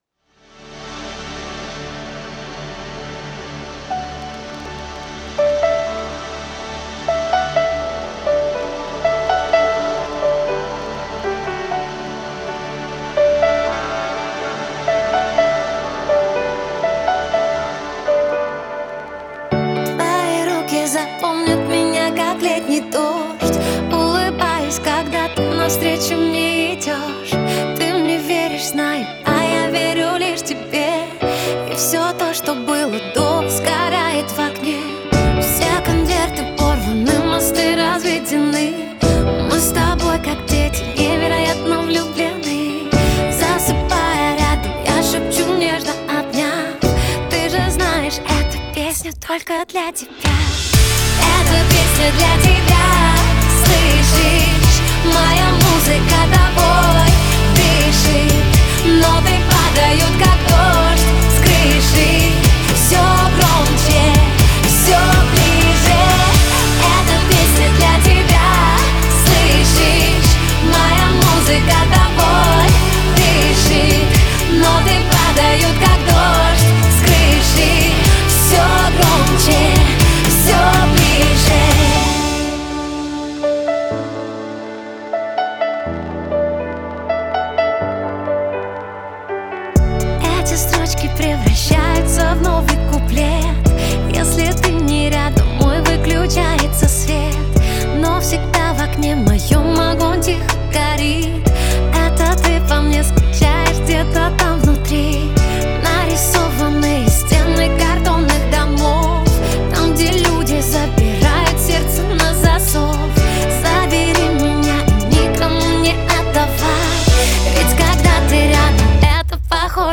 Жанр: Русская музыка